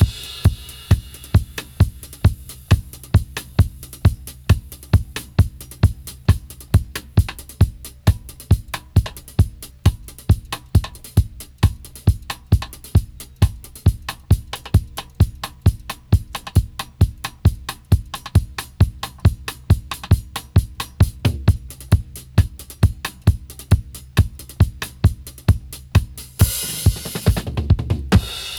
134-DRY-03.wav